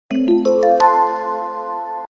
• Качество: 128, Stereo
Классная стандартная мелодия смс